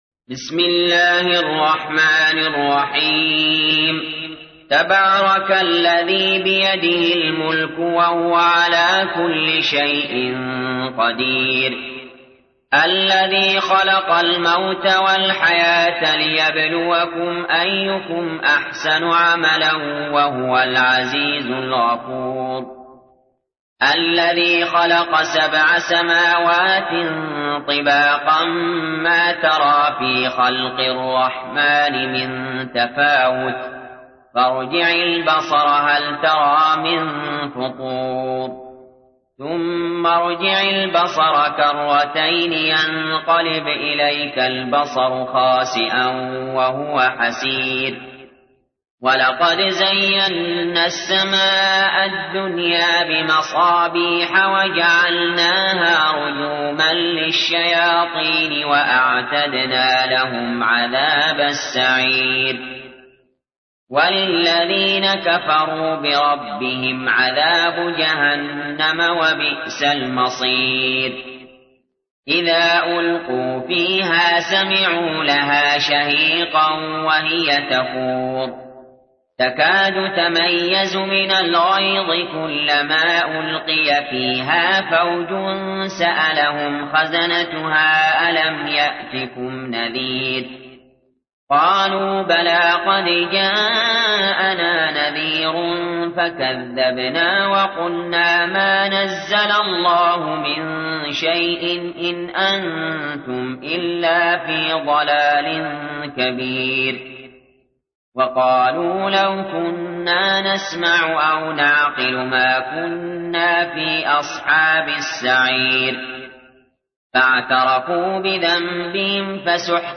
تحميل : 67. سورة الملك / القارئ علي جابر / القرآن الكريم / موقع يا حسين